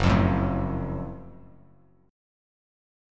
GbmM7 chord